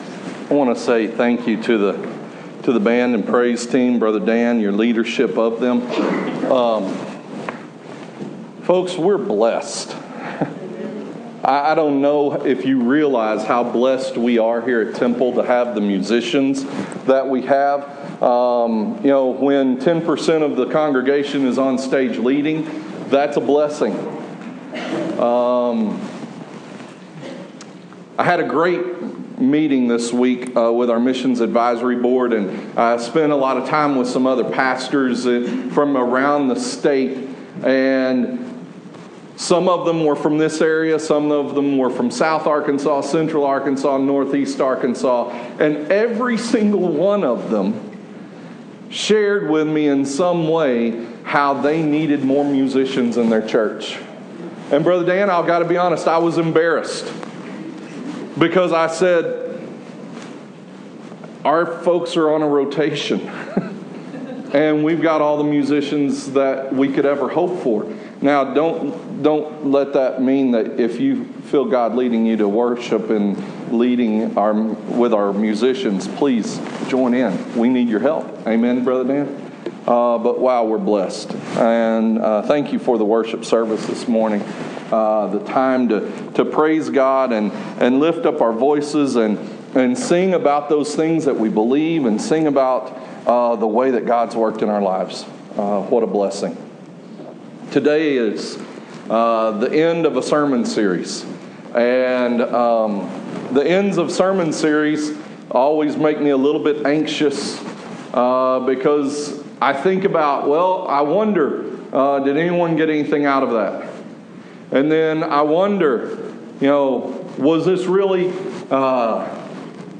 Acknowledging the Presence of the Spirit – Sermon 10 – Temple Baptist Church of Rogers, AR
Acknowledging the Presence of the Spirit – Sermon 10